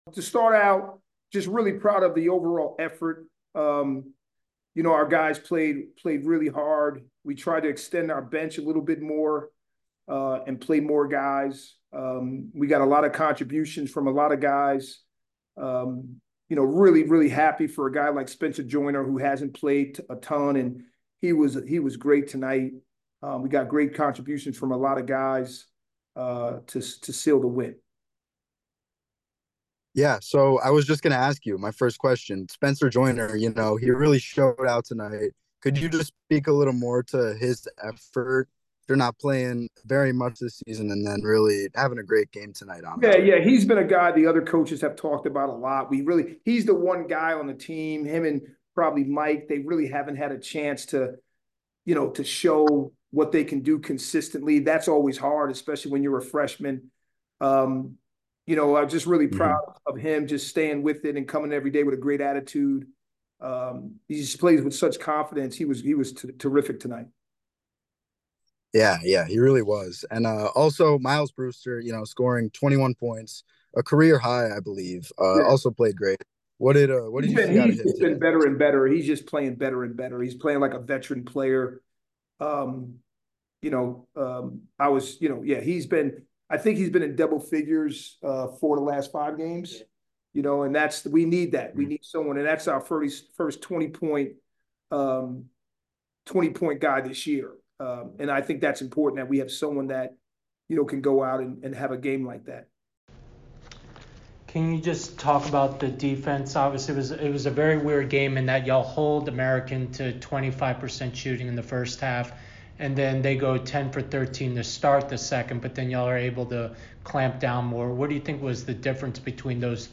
American Postgame Interview